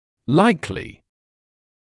[‘laɪklɪ][‘лайкли]вероятно; вероятный, возможный